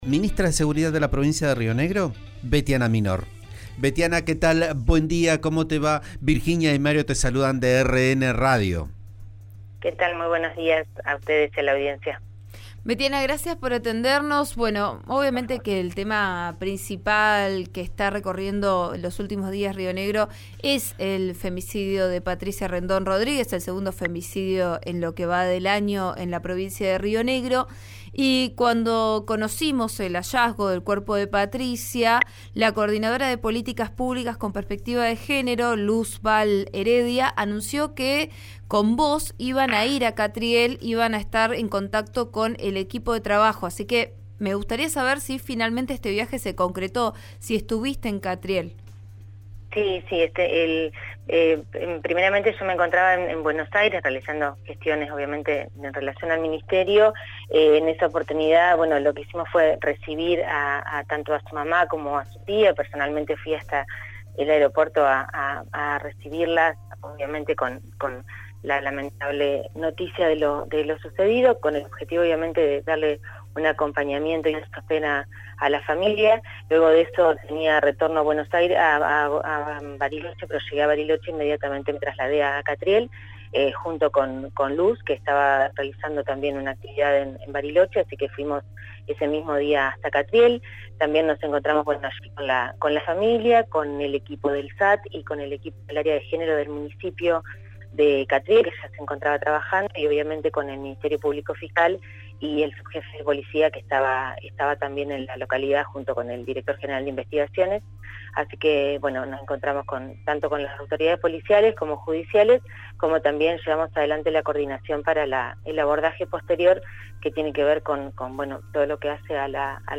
En declaraciones a «Vos a Diario» por RN RADIO, la funcionaria detalló el trabajo que hace la provincia con las masculinidades a la vez que reveló que es una materia pendiente en la provincia.